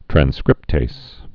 (trăn-skrĭptās, -tāz)